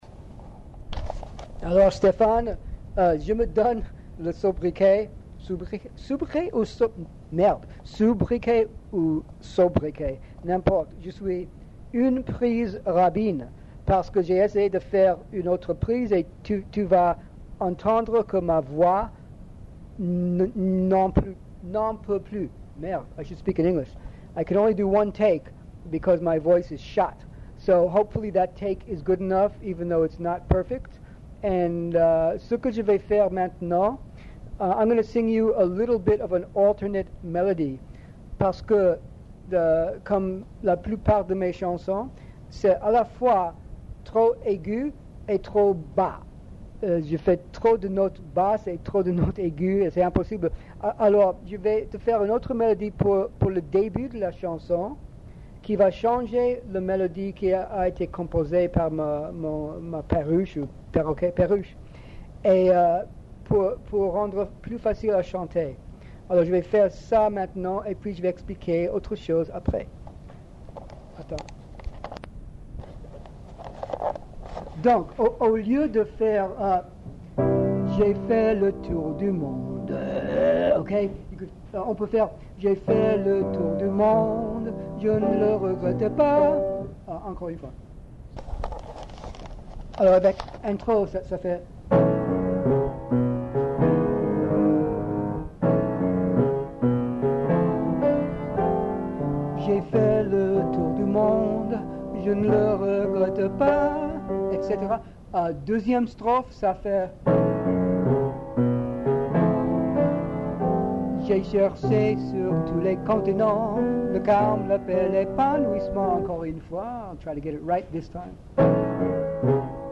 pianiste
Voici la premiere demo enregistr� sommairement sur une k7 Audio.